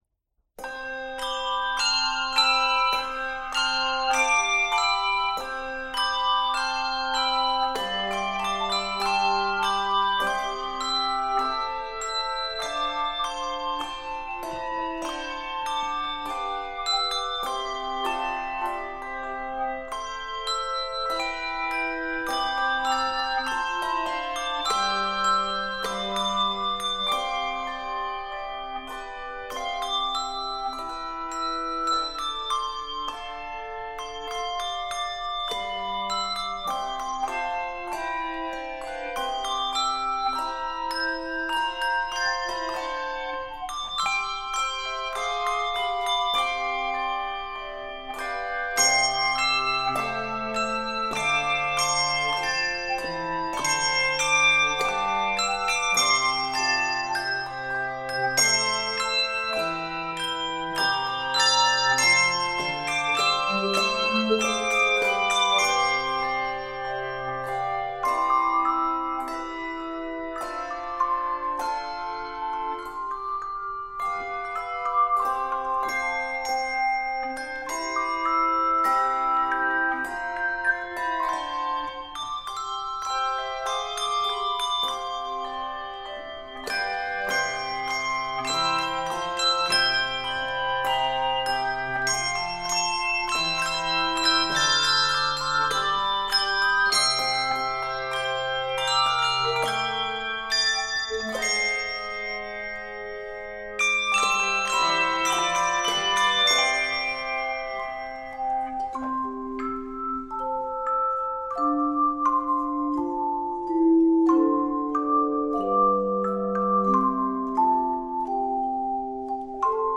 Not overly syncopated
Key of C Major.